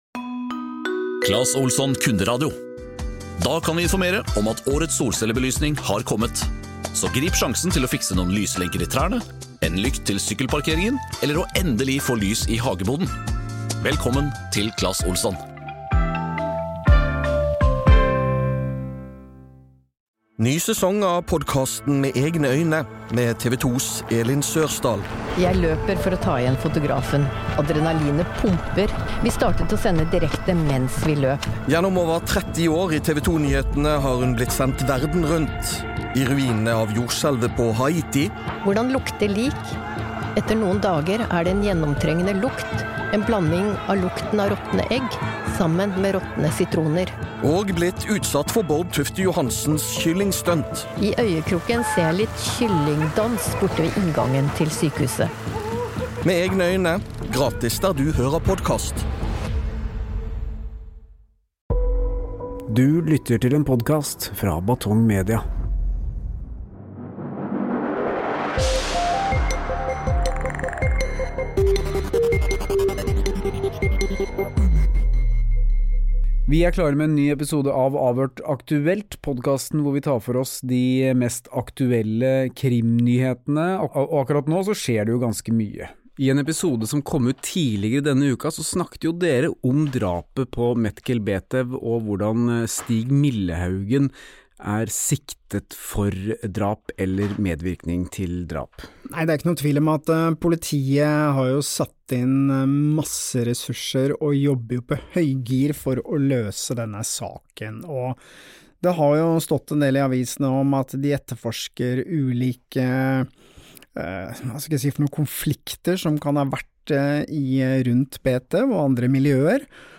Litt over halvveis kommer det et lite brudd i samtalen, da vi hadde behov for en liten beinstrekk.